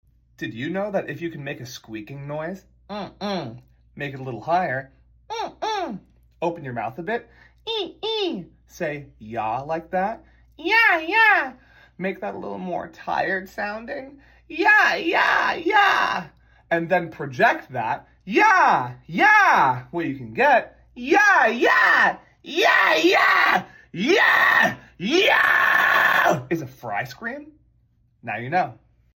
Use this to learn Fry Scream!